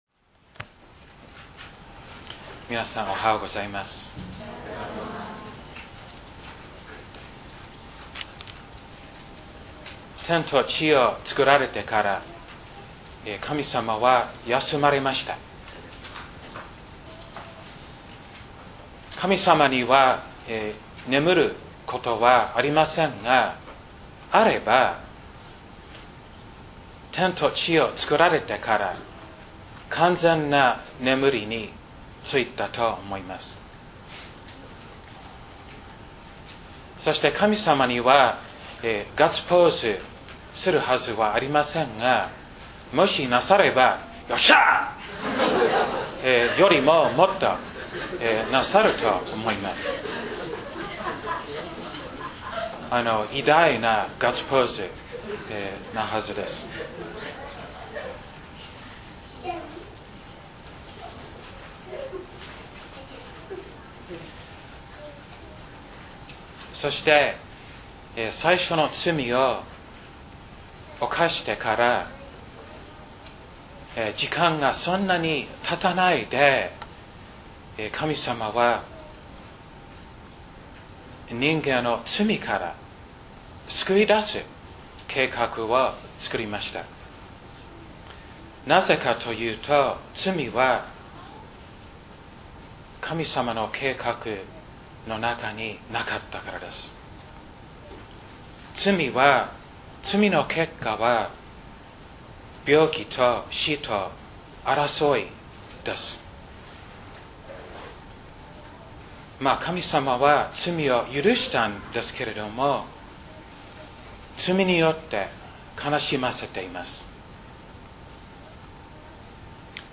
礼拝宣教録音－安息のさまたげ